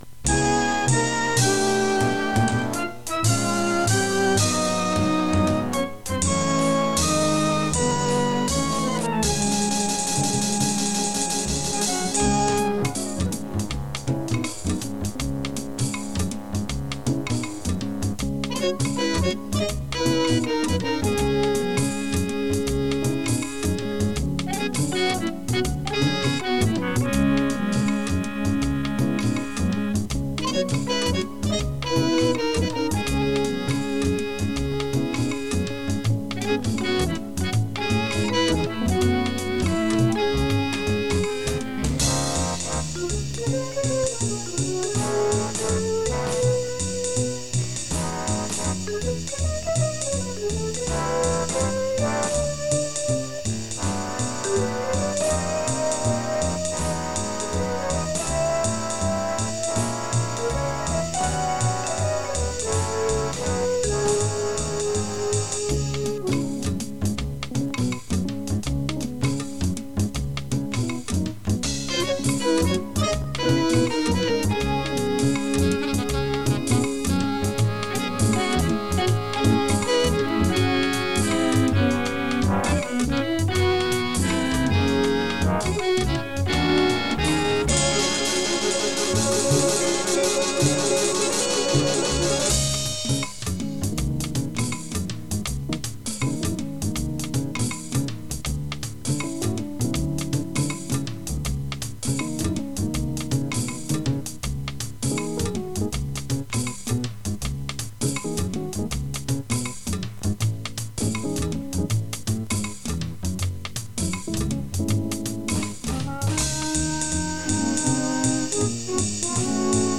MORE TO PLAY-ALONG WITH